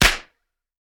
Звуки выстрелов
Один пистолетный выстрел